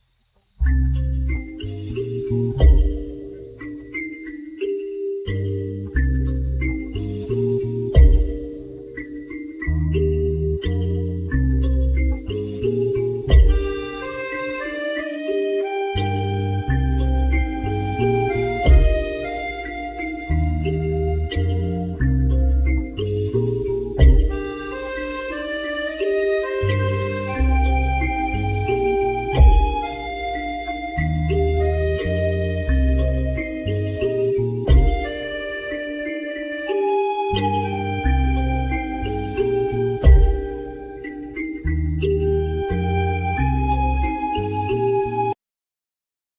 other musicians   vocals
guitar
bass
percussion,samples
synth,drums,udo
flute